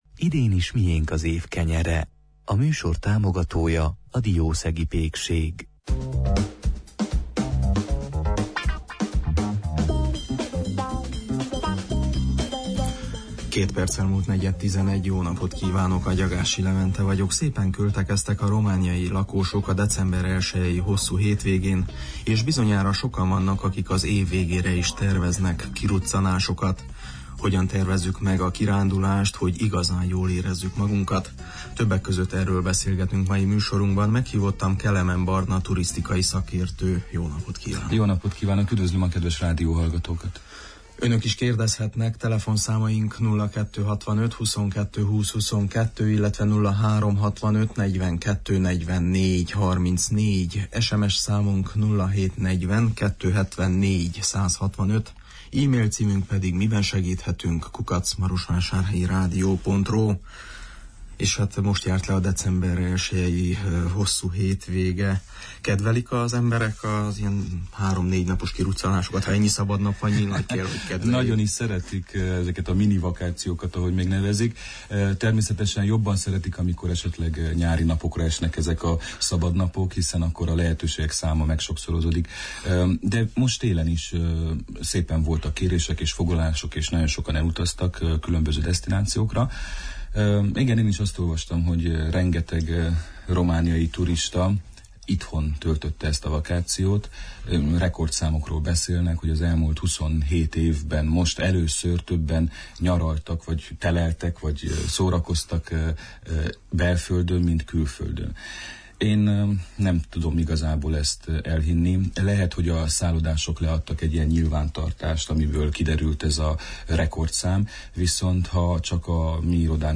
turisztikai szakértő volt, aki utazási tudnivalókkal, kedvelt célpontokkal kapcsolatos kérdésekre válaszolt.